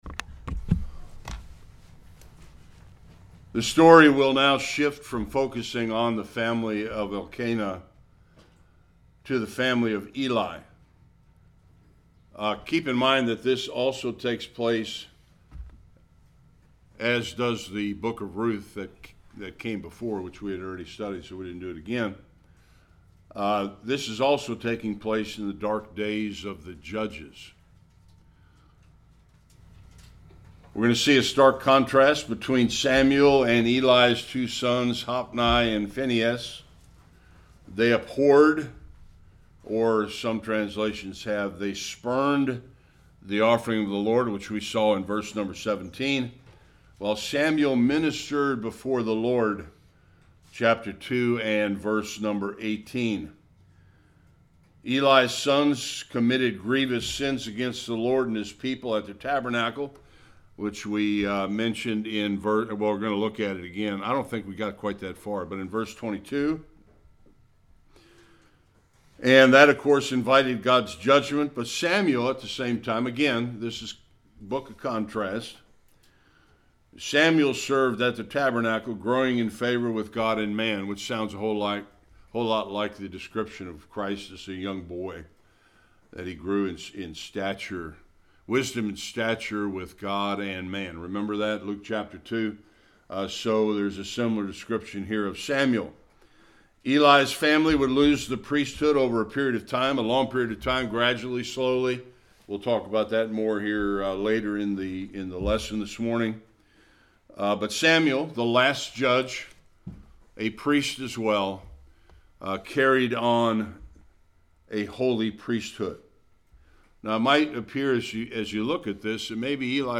1-10 Service Type: Sunday School The evil sons of Eli compared to righteous Samuel.